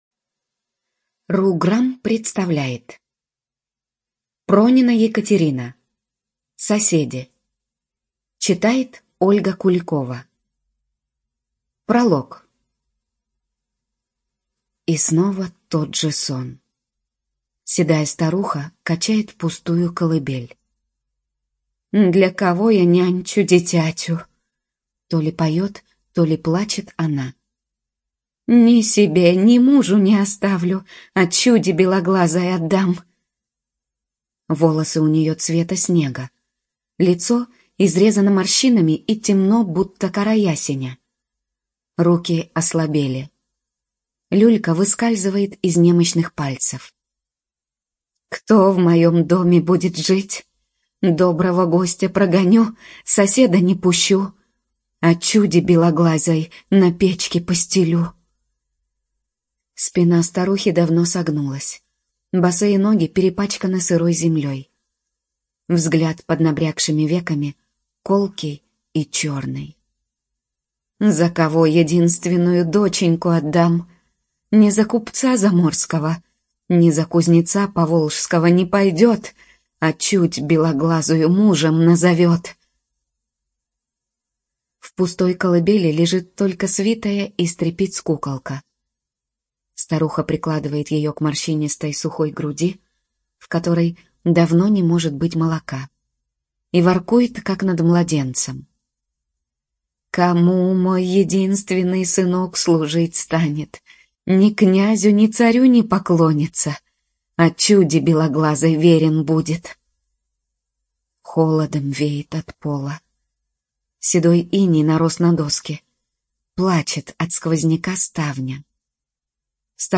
ЖанрУжасы и мистика
Студия звукозаписиRugram